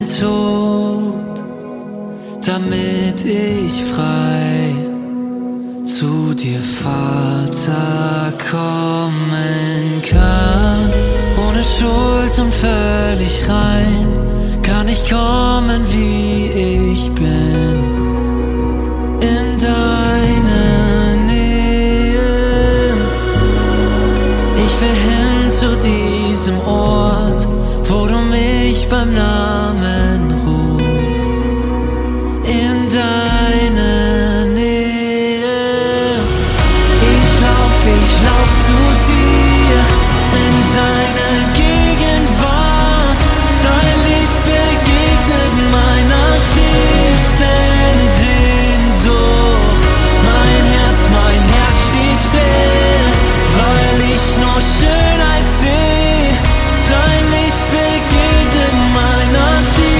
Kraftvoll und intensiv.
Die Songs tragen ein neues Gewand im singbaren Stil.
Gesang.